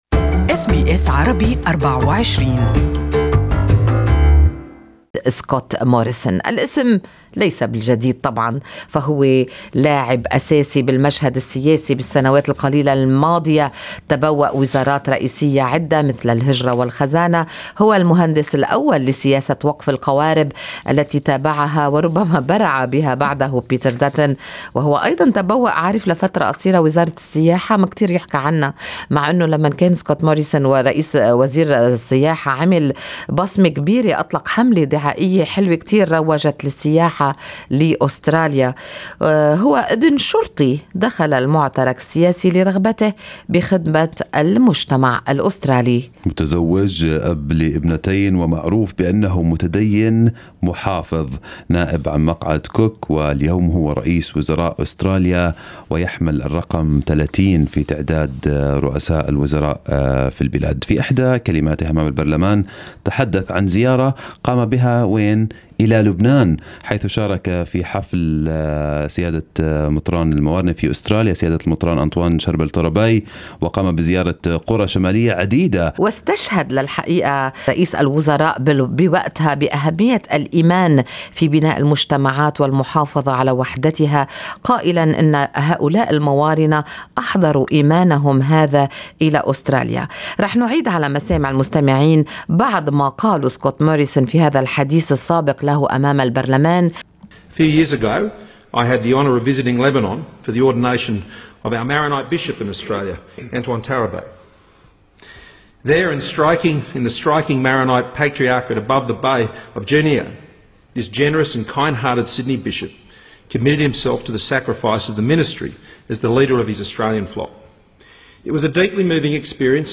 Good Morning Australia interviewed Maronite Bishop Antoine-Charbel Tarabay to talk about PM Scott Morrison's visit to Lebanon 5 years ago, his religious views and strong support of the traditional family. Tarabay also discussed the Church's response to the rising demands of breaking the "Seal of Confession" and how it contradicts with religious freedom in the country.